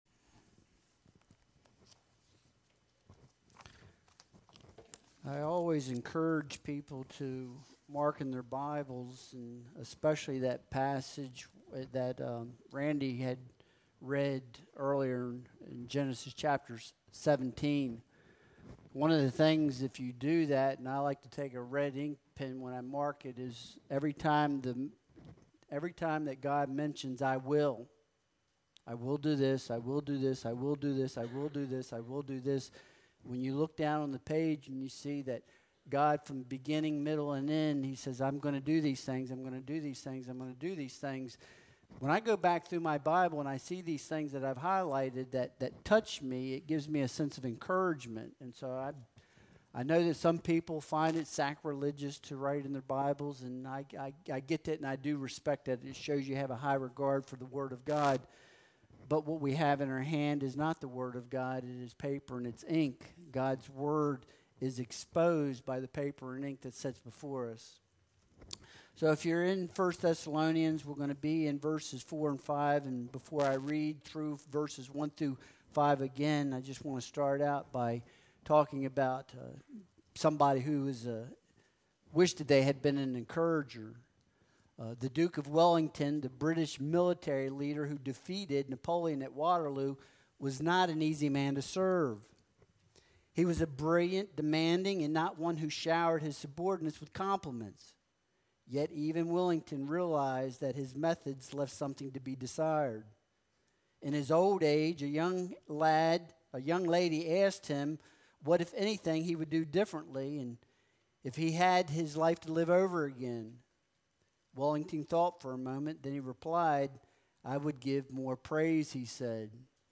1 Thessalonians 1.4-5 Service Type: Sunday Worship Service Download Files Bulletin « Imitation & Witness Election